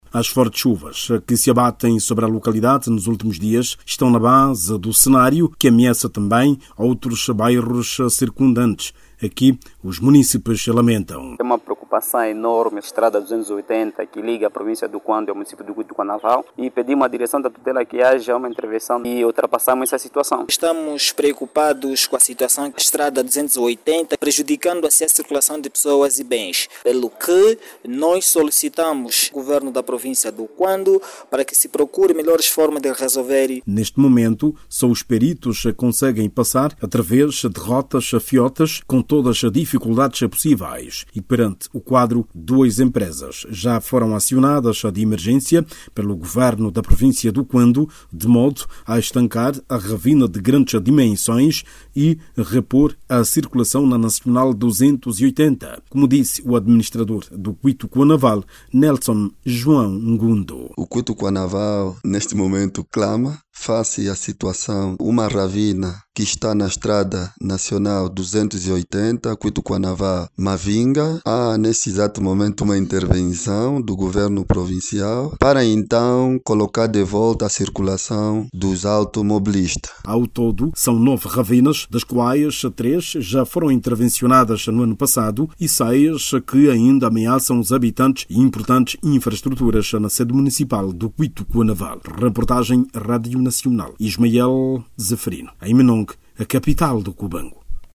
Uma ravina de grandes dimensões cortou a circulação entre os municípios do Cuíto Cuanavale e Mavinga, na província do Cuando. A ravina, que engoliu um troço da estrada nacional 280, está a dificultar a circulação de pessoas e mercadorias e ameaça destruir alguns bairros. As fortes chuvas que caem na região agravam a situação. Saiba mais dados no áudio abaixo com o repórter